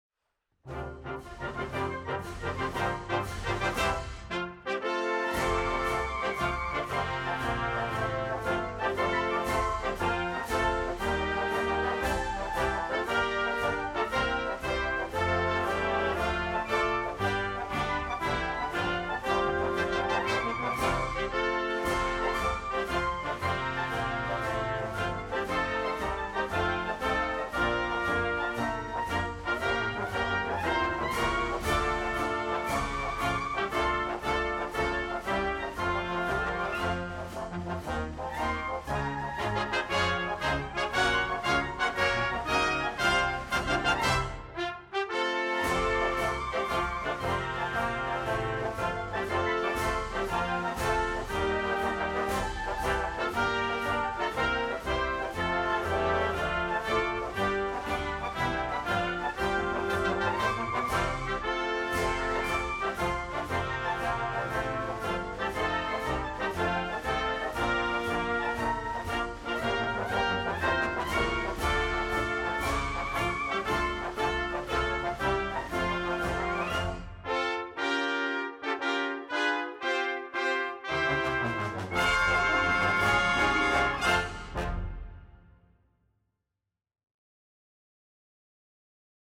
USSFS-Instrumental-Long.wav